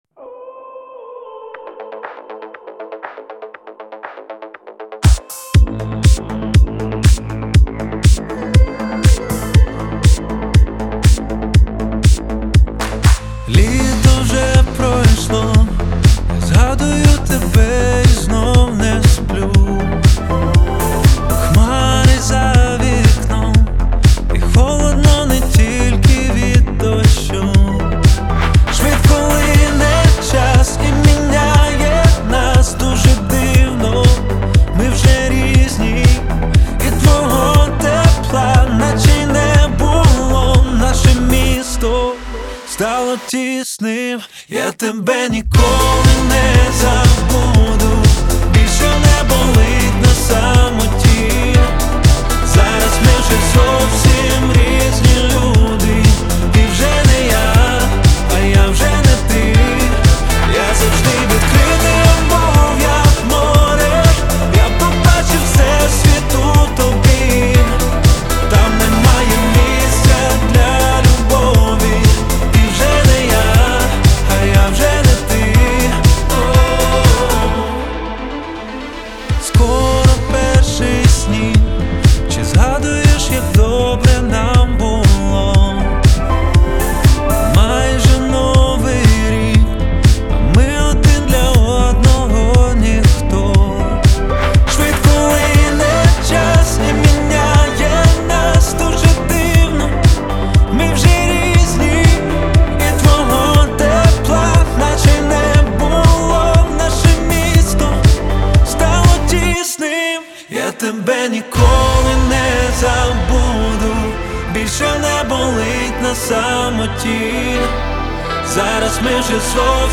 • Жанр: Українські пісні